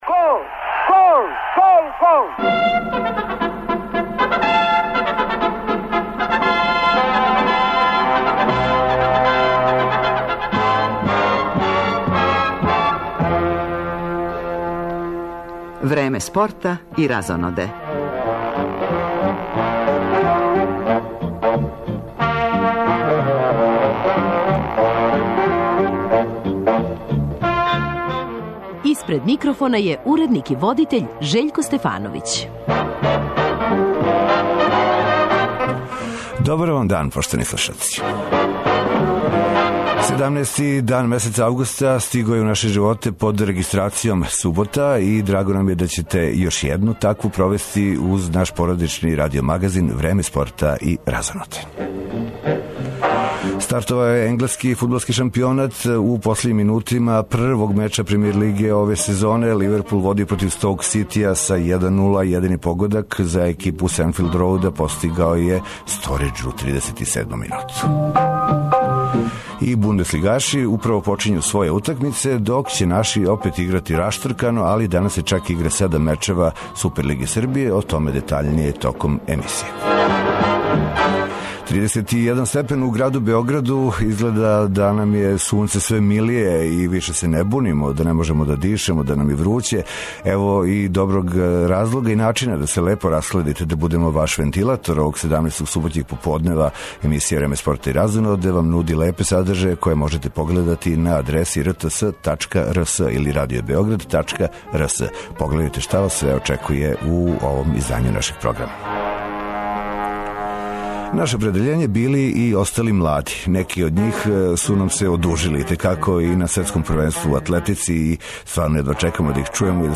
На ову тему разговарамо са нашим прослављеним спортистом Ненадом Стекићем, који не штеди речи хвале на рачун нове генерације краљице спортова код нас.